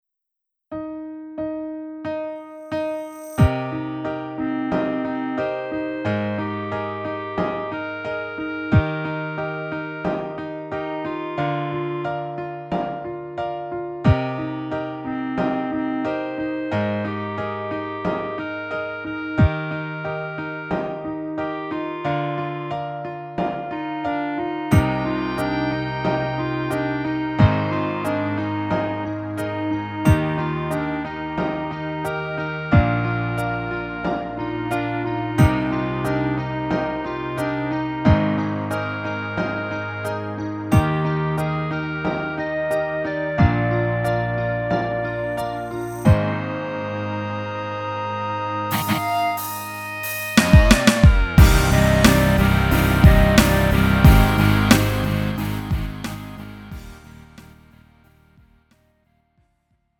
음정 -1키 3:30
장르 가요 구분 Lite MR